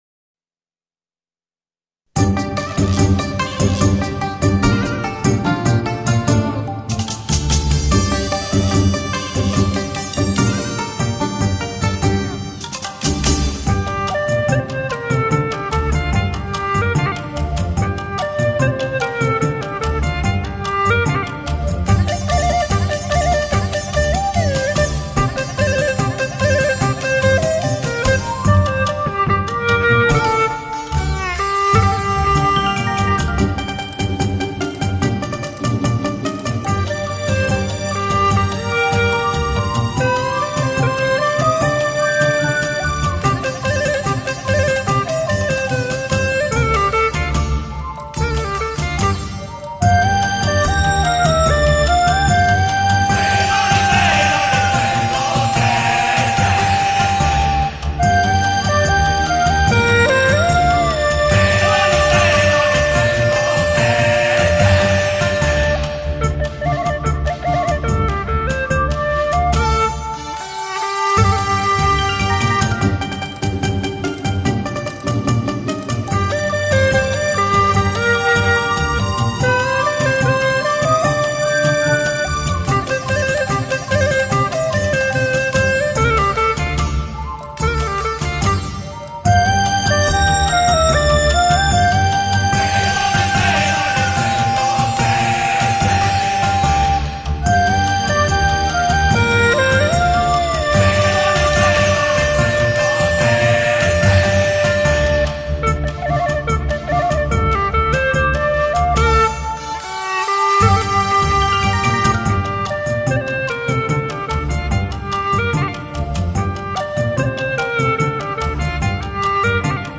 葫芦丝